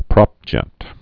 (prŏpjĕt)